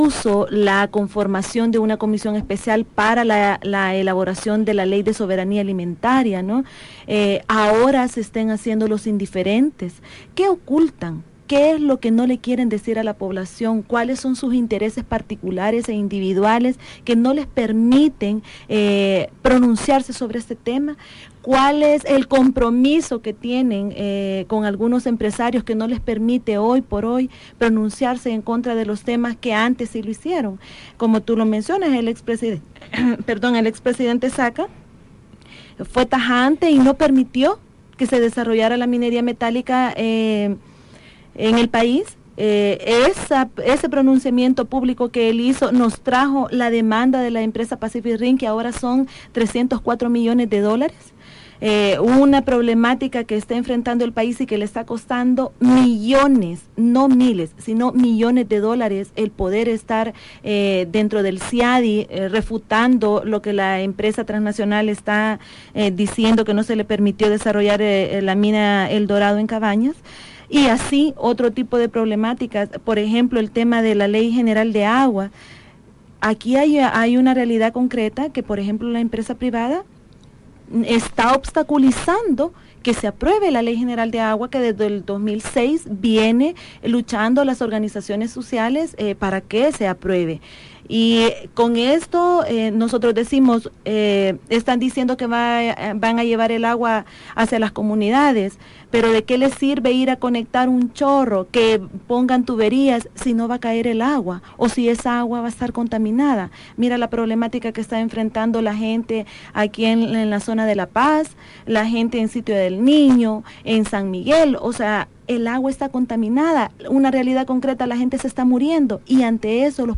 Entrevista en radio YSUCA 91.7 FM